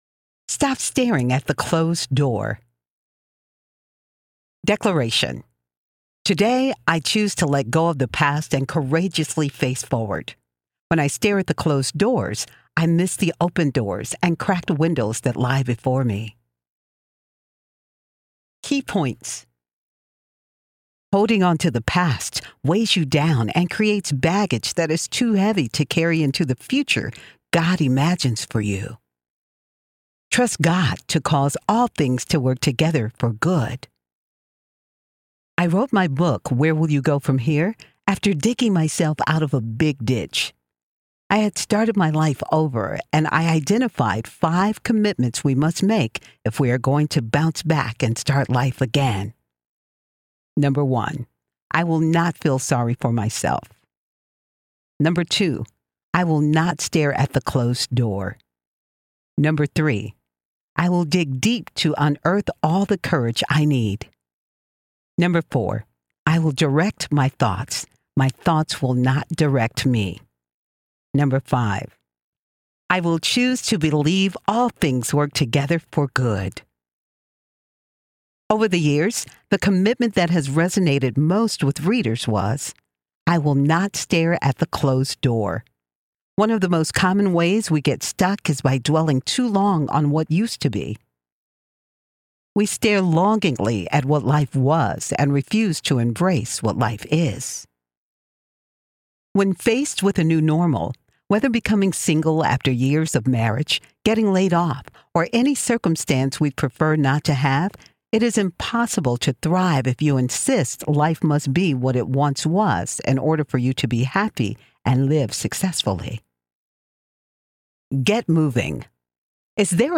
Brave Enough to Succeed Audiobook
Narrator
4.82 Hrs. – Unabridged